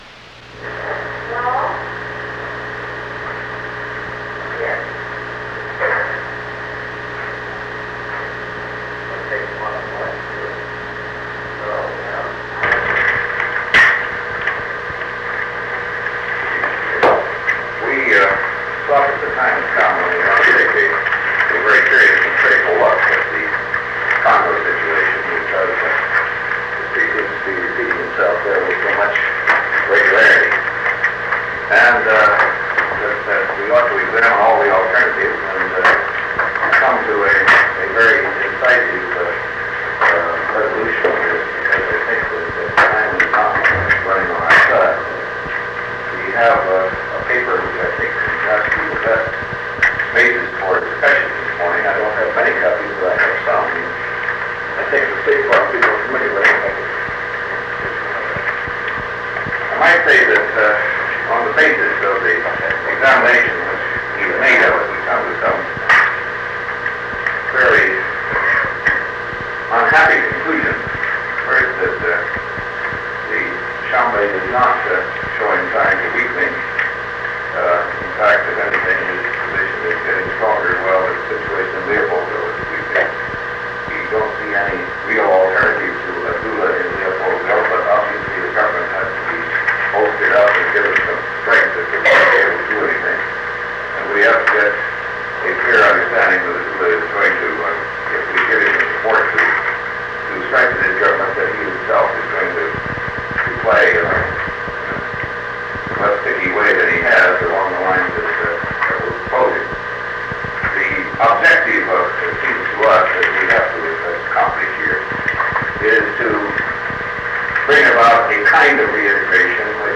Secret White House Tapes | John F. Kennedy Presidency Meeting on the Congo Rewind 10 seconds Play/Pause Fast-forward 10 seconds 0:00 Download audio Previous Meetings: Tape 121/A57.